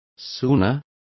Complete with pronunciation of the translation of sooner.